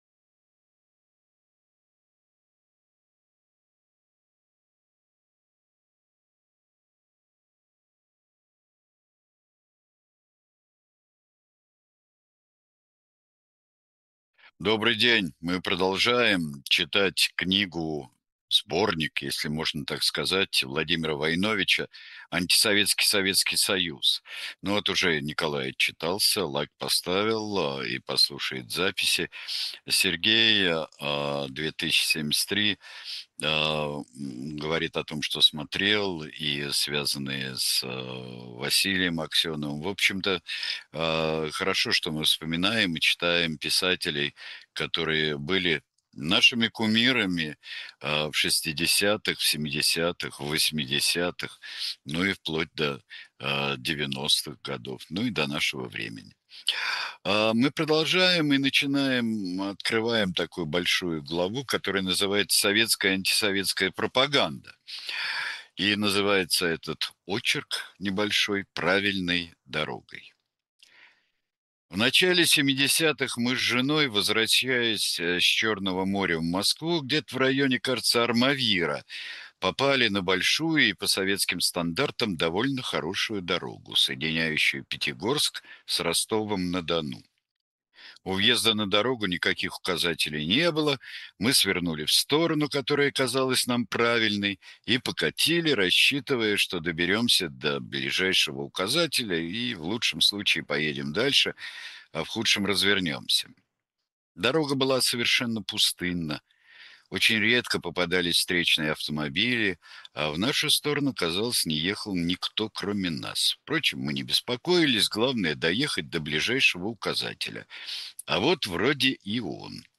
Читает произведение Сергей Бунтман